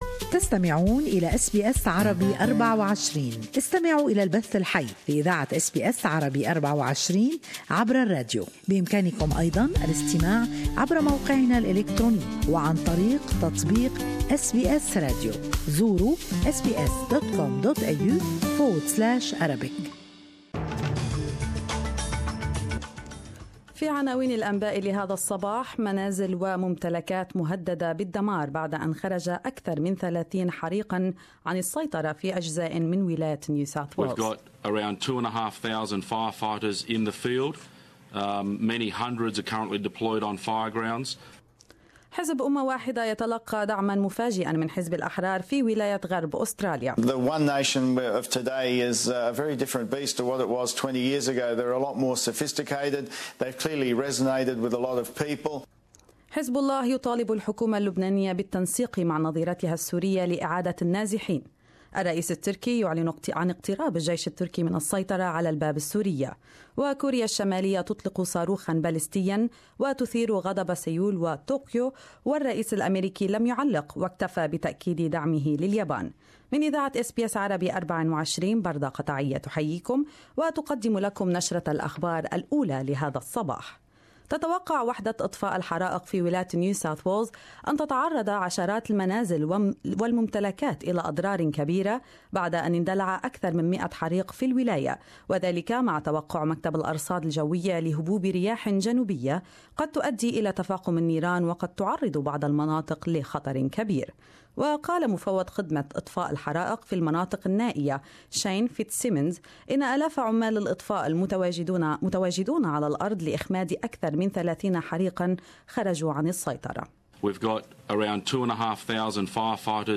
News Bulliten 13-2-17